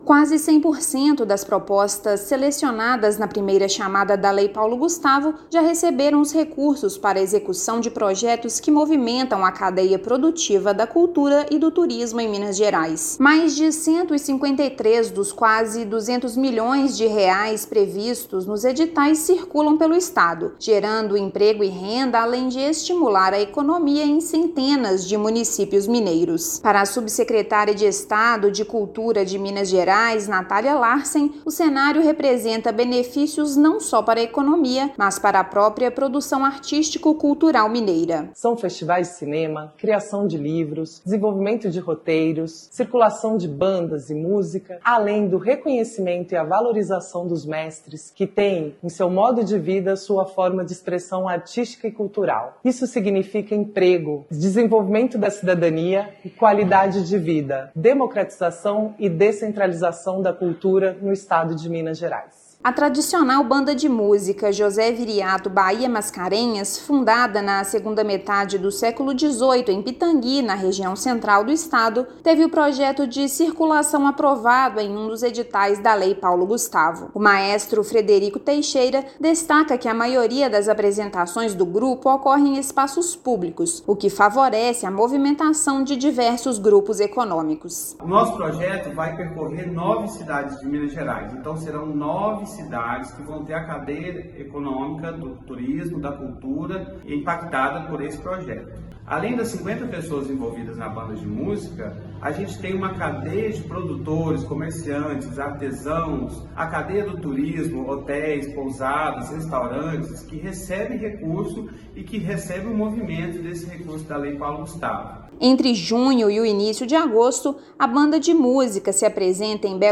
Iniciativas, já em execução em várias regiões do estado, estimulam a produção artística e geram benefícios diversos à economia da criatividade. Ouça matéria de rádio.